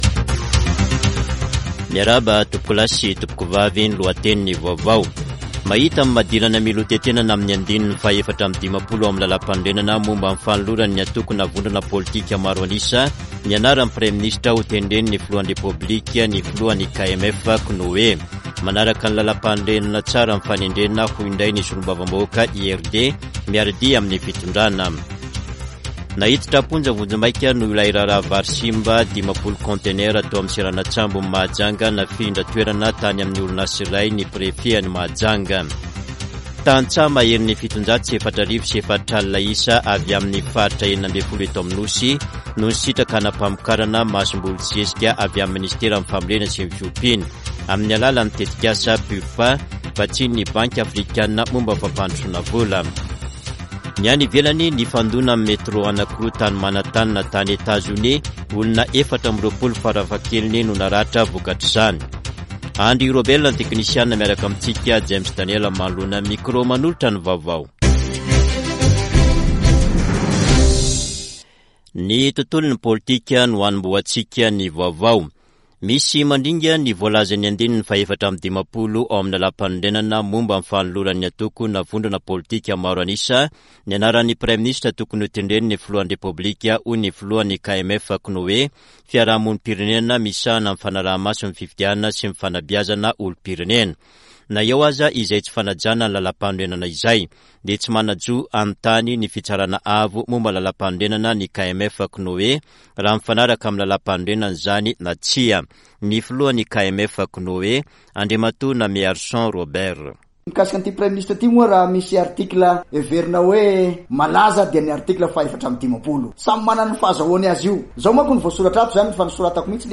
[Vaovao hariva] Zoma 5 janoary 2024